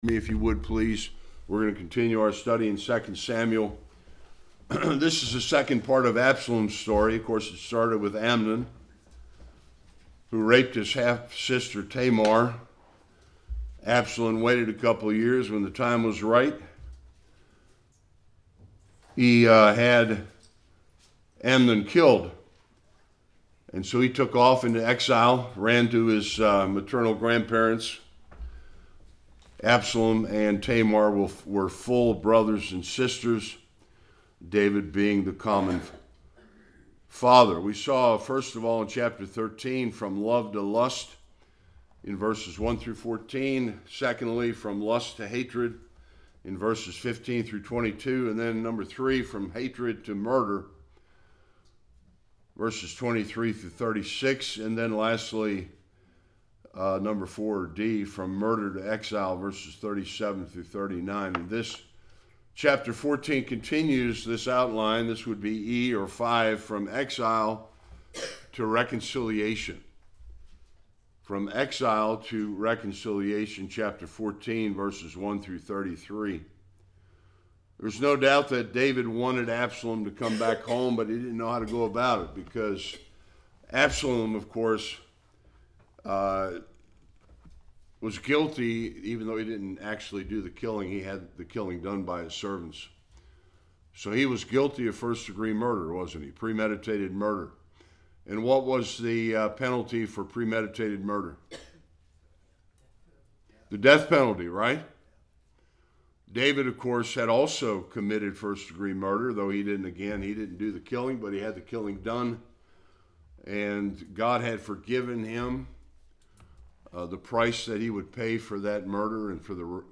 1-33 Service Type: Sunday School Joab’s plan to get King David and his son Absalom to reconcile.